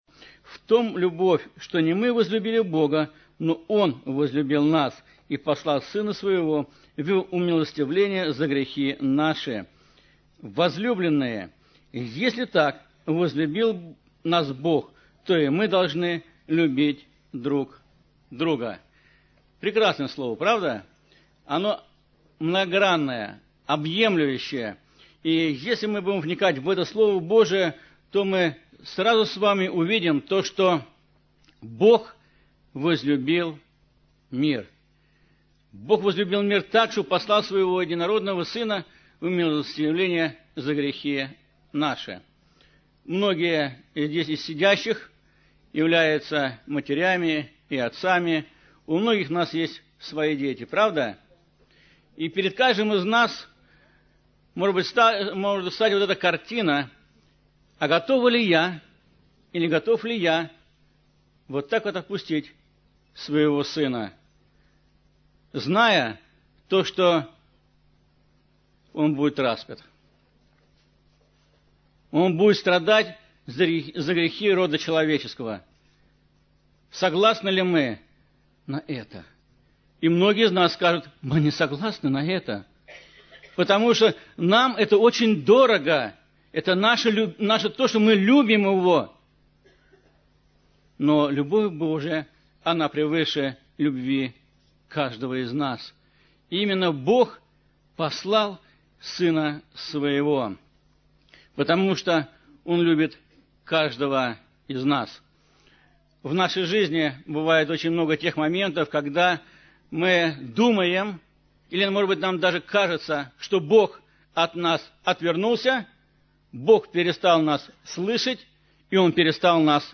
Церковь: Церковь ЕХБ "Голгофа", г. Москва (Местная религиозная организация – Церковь евангельских христиан-баптистов «Голгофа»)